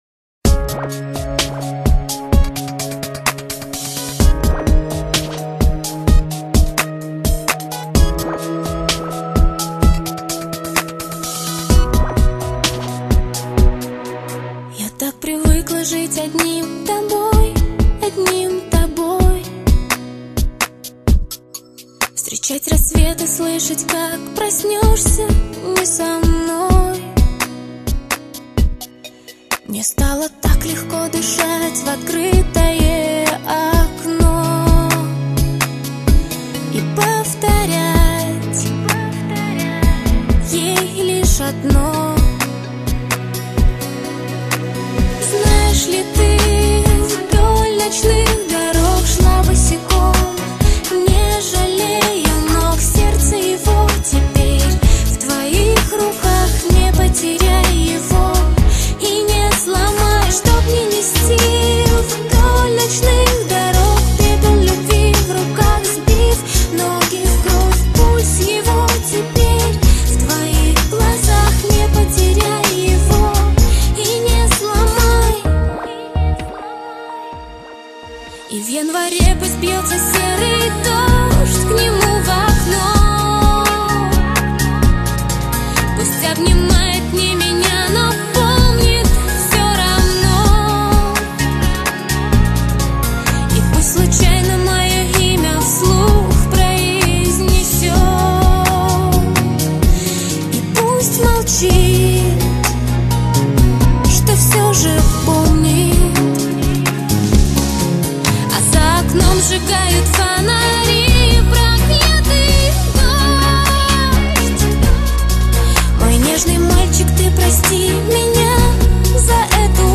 RnB mix